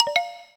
gamesave.ogg